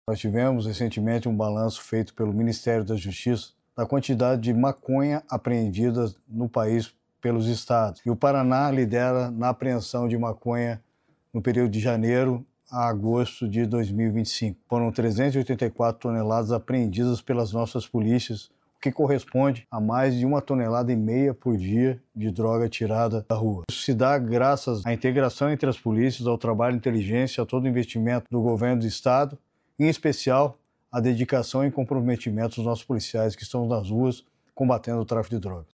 Sonora do secretário da Segurança do Estado do Paraná, Coronel Hudson Teixeira, sobre a grande apreensão de maconha